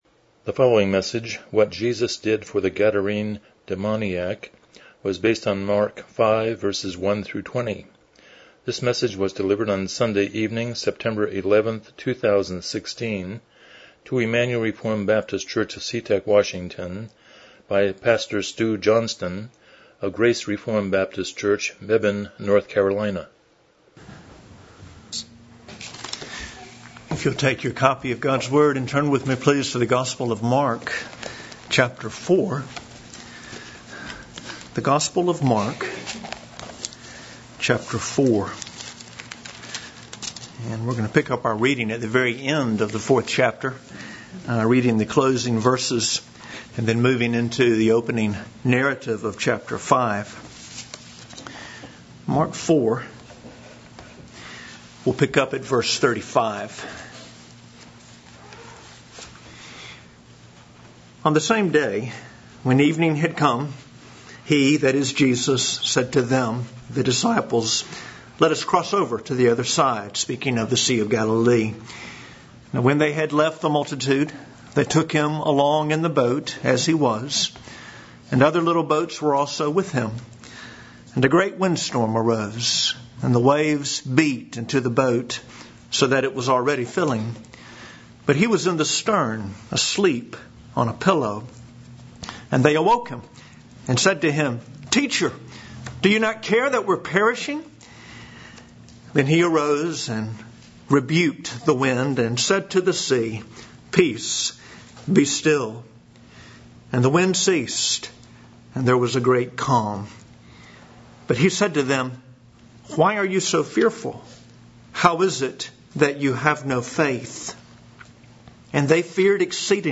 Passage: Mark 5:1-20 Service Type: Evening Worship